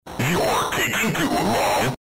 youre taking too long Meme Sound Effect
youre taking too long.mp3